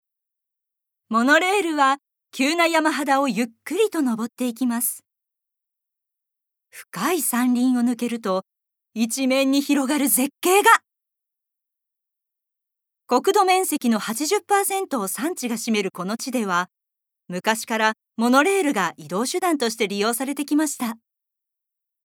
Voice Sample
ナレーション４